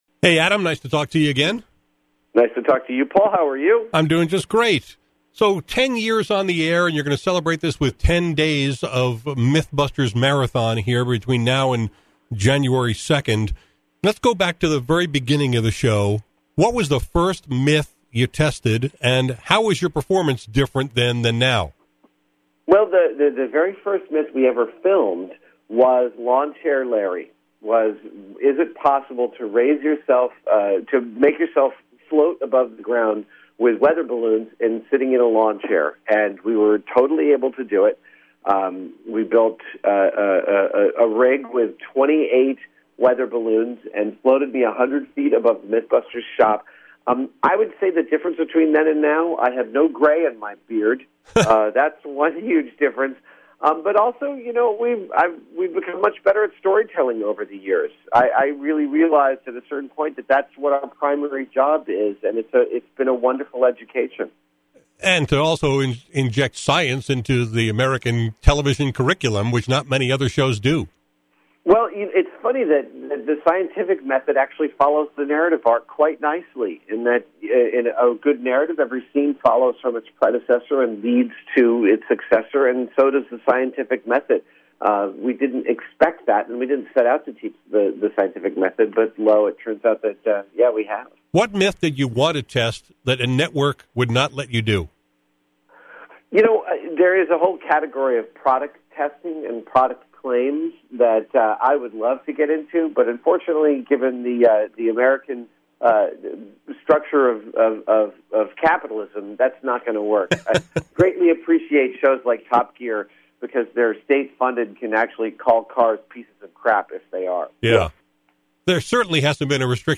Adam Savage returned to my show to talk about the “Mythbusters” marathon (every episode from all ten seasons) that’s airing through January 2nd on The Science Channel.